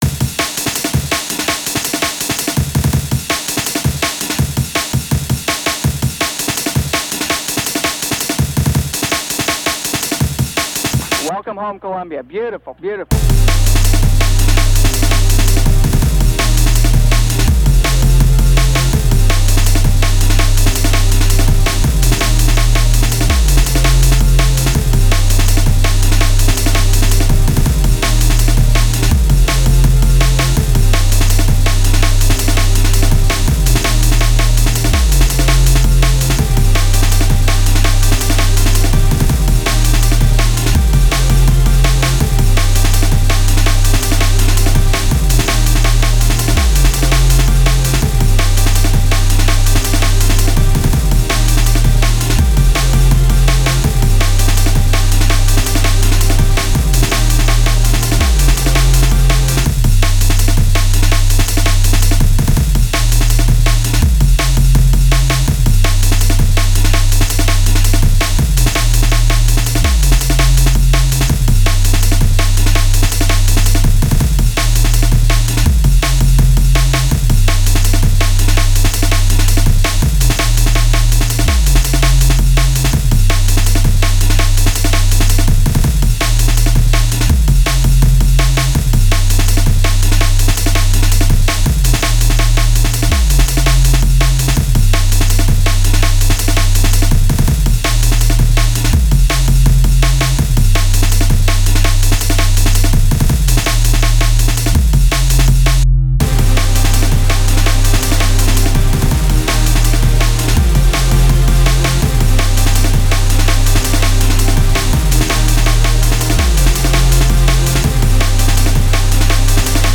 new drum break sample yippee
breakcore dnb